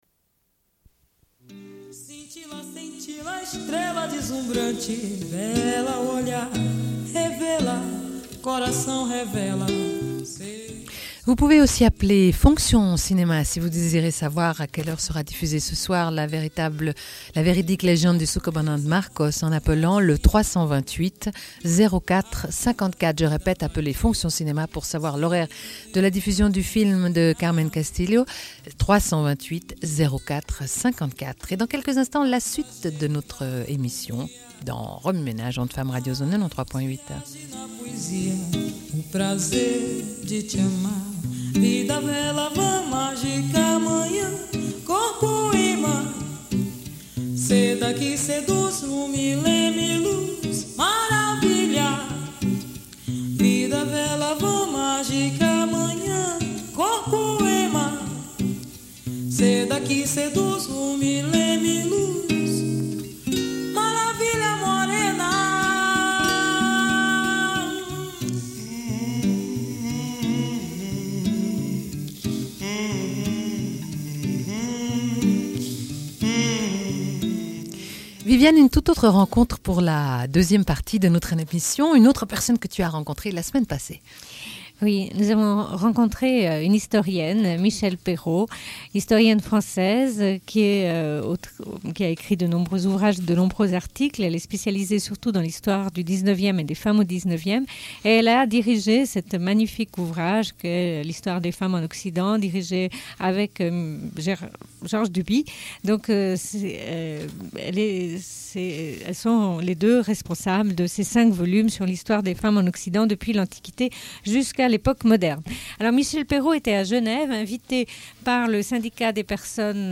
Une cassette audio, face A31:05